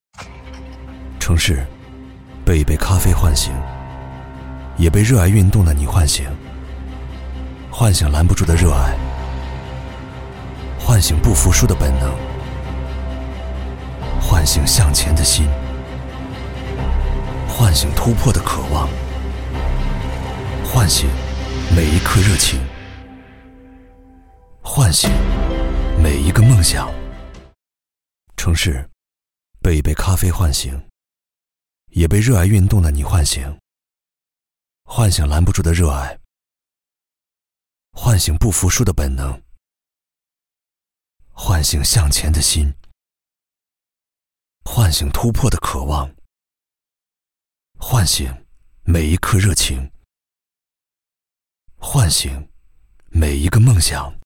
Clean audio with no breaths or mouth noises
Sennheiser MKH 416 Mic, UA Volt 276 Interface, Pro Recording Booth, Reaper
BarítonoBajo